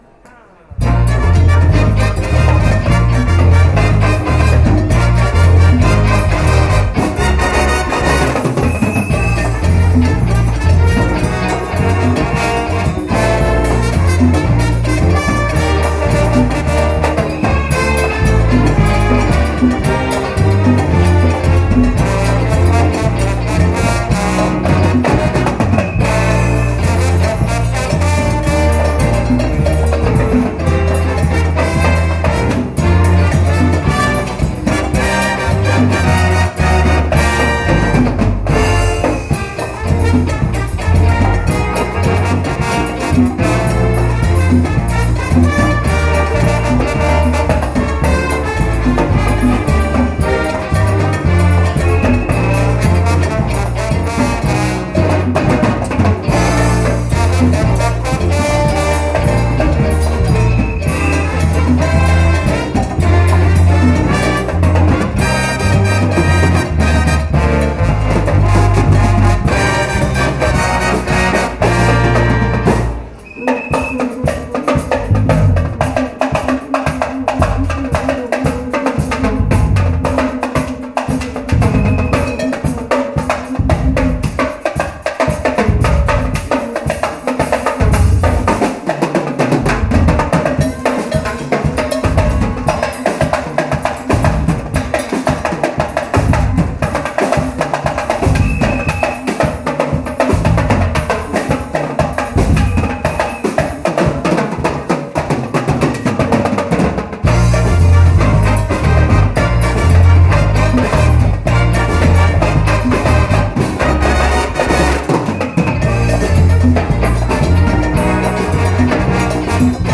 M.P.C. Superband '83 - Banda Musicale Comunale di Monte Porzio Catone
STANDING CONCERT …  possibilità di esibizione e concerto a terra.